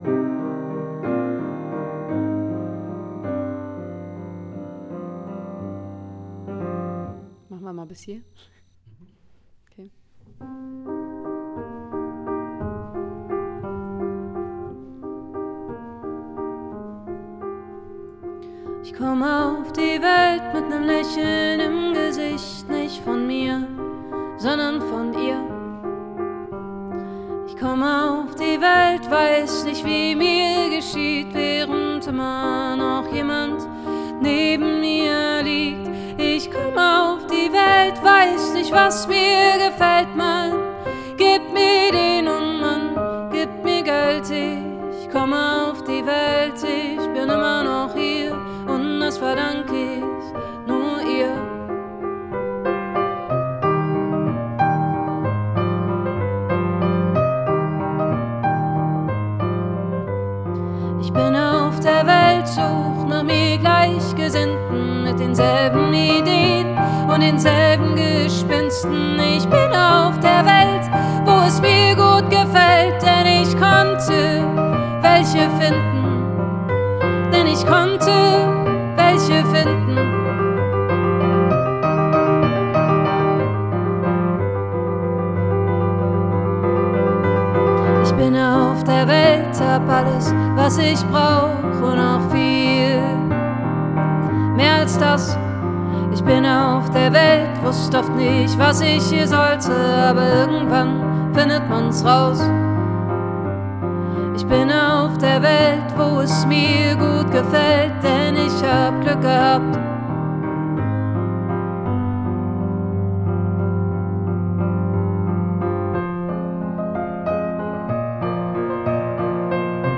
How Do You Record Your Acoustic Piano?